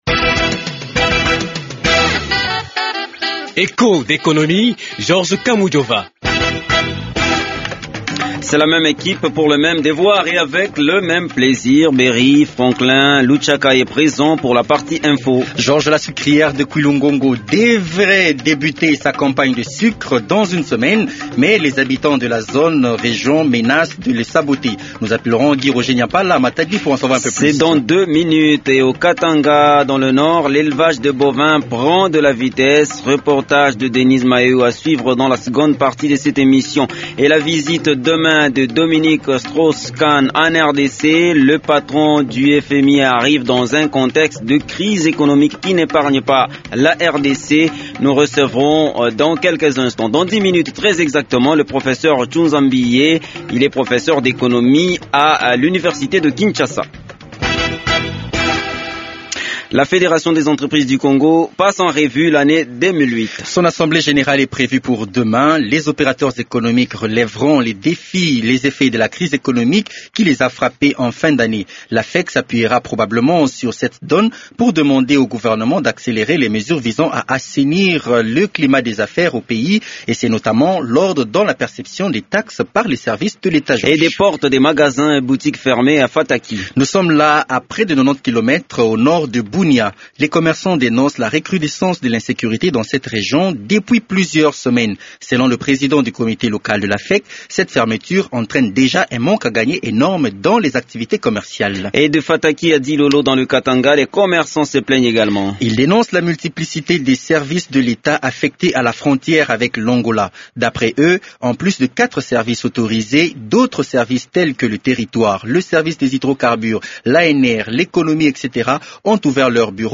L’invité de l’émission est le Professeur Tshunza Mbiye, ancien ministre de l’economie sous Mobutu, ancien vice-gouverneur de la BCC et Professeur d’économie a l’Université de Kinshasa. Avec lui, nous évoquons les attentes de la RDC sur la visite de Dominique Strauss Kahn. Echos d’économie vous fait voyager dans le district de Haut-Lomami au Katanga ou l’élèvage de bovins prend de la vitesse.